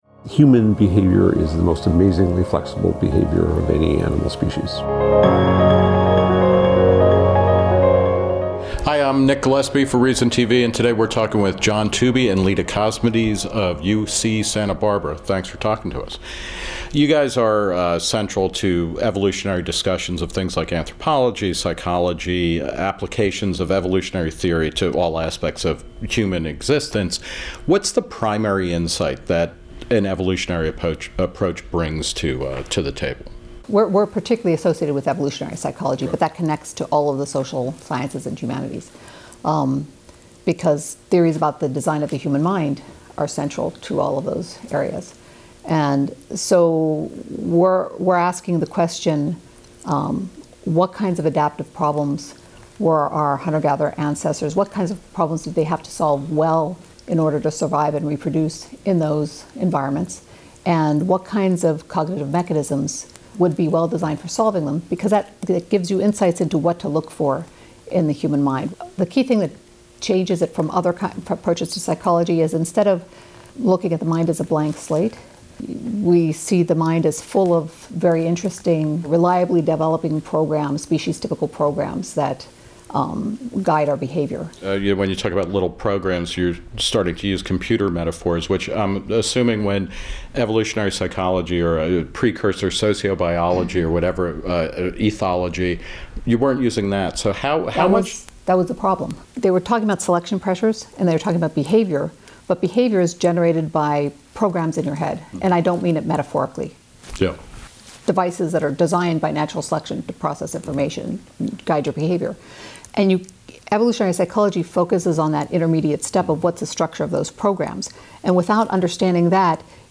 Hacking the Programs in Your Mind: Interview with Evolutionary Psychologists Leda Cosmides & John Tooby